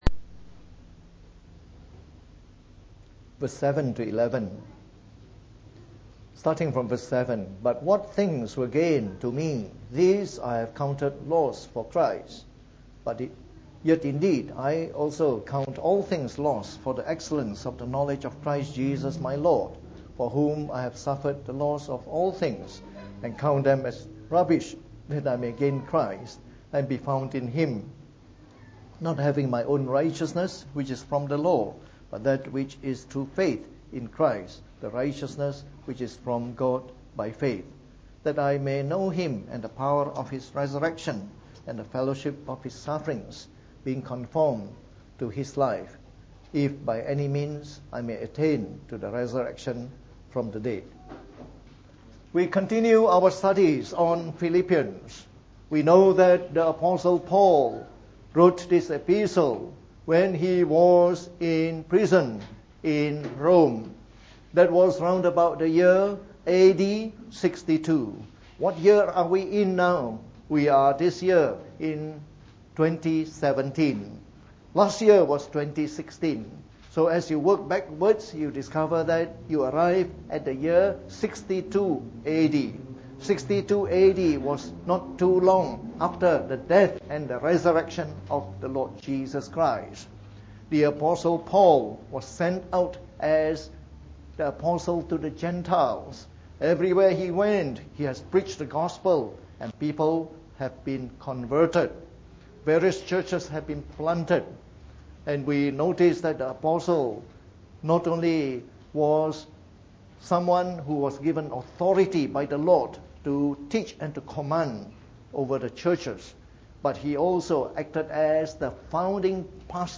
From our series on the Epistle to the Philippians delivered in the Morning Service.